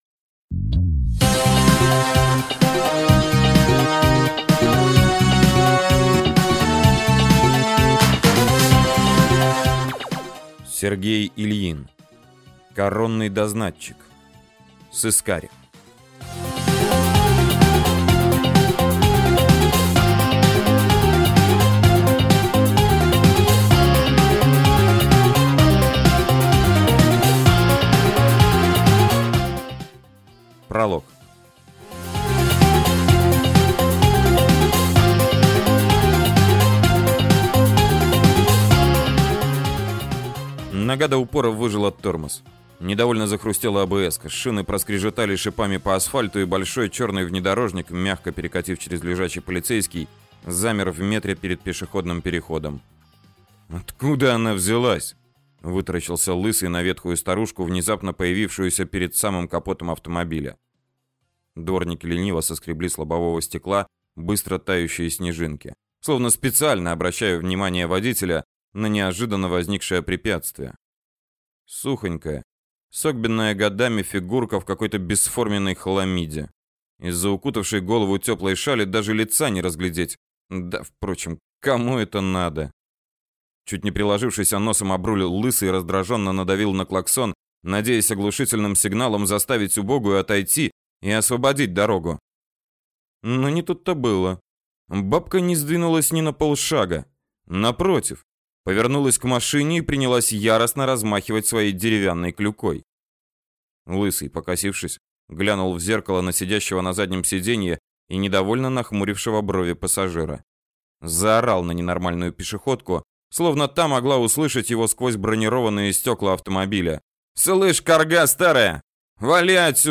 Аудиокнига Коронный дознатчик. Сыскарь | Библиотека аудиокниг